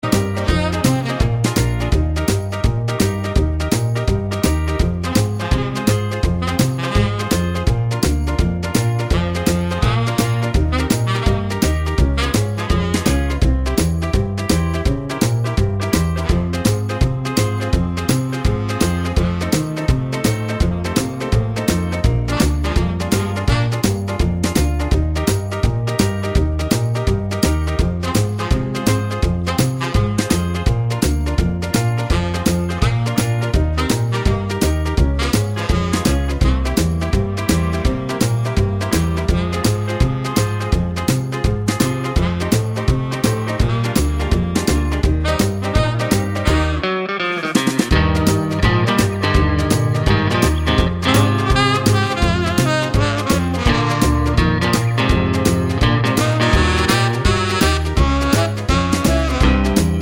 Down 3 Semitones Soul / Motown 2:31 Buy £1.50